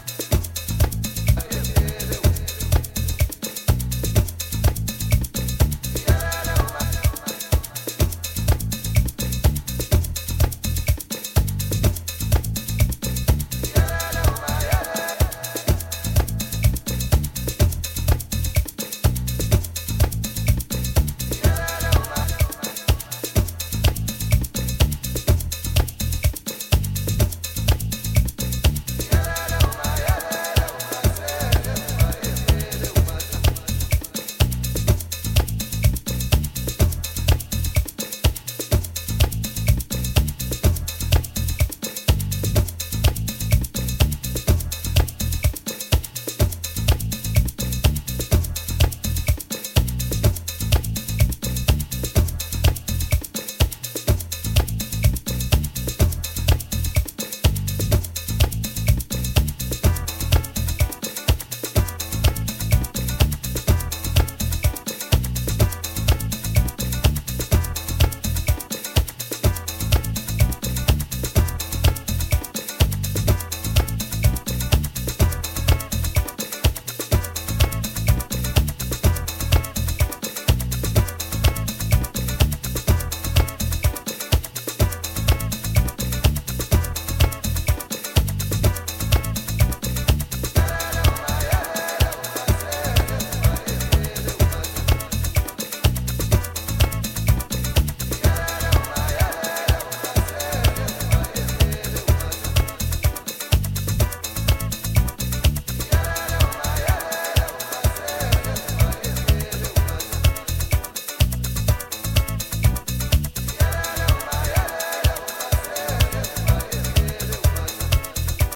edits and overdubs of African vibrational sounds and rhythms